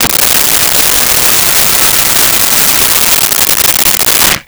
Blender On Puree
Blender on Puree.wav